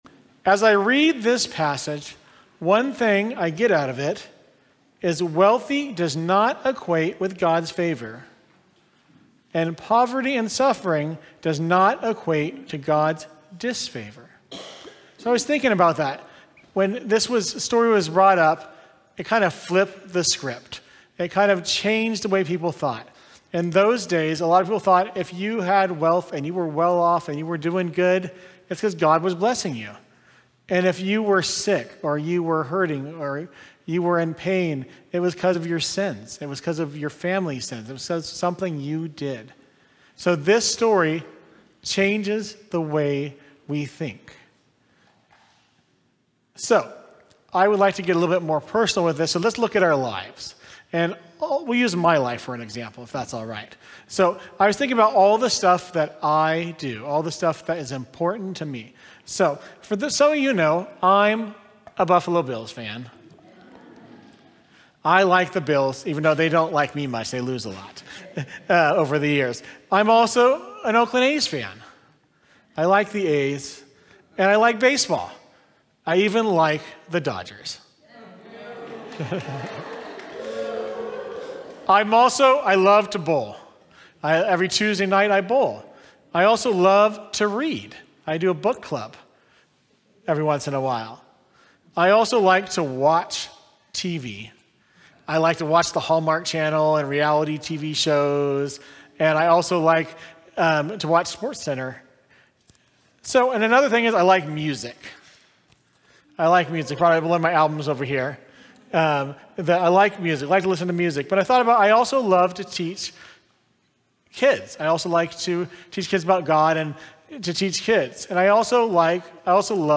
Sermon from Sunday, September 28, 2025